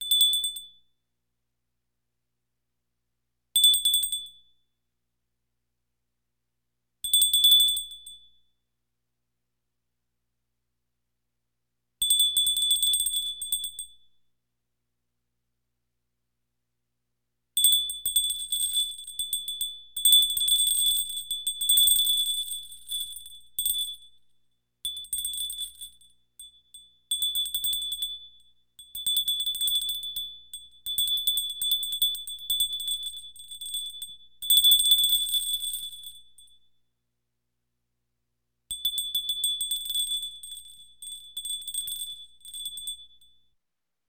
Dinner Bell Sound Effect Free Download
Dinner Bell